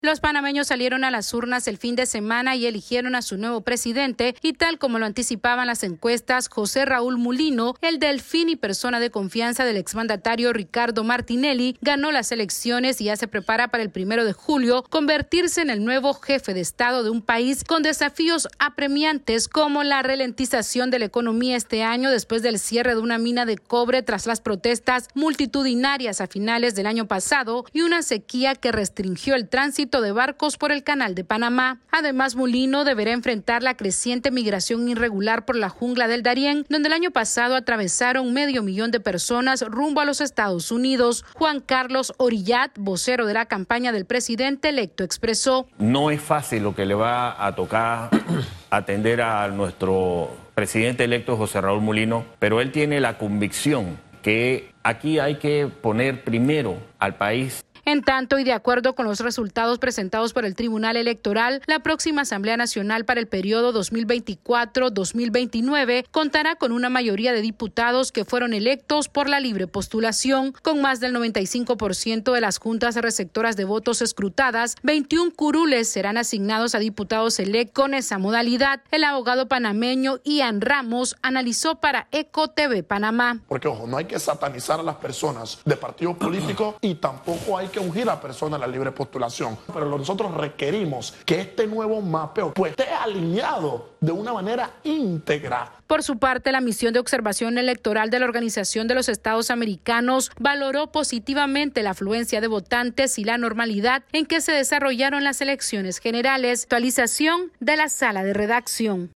Panamá analiza los apremiantes desafíos que enfrentará el presidente electo, José Mulino, mientras termina de conformarse la nueva Asamblea Nacional mayoritariamente compuesta por diputados independientes. Esta es una actualización de nuestra Sala de Redacción...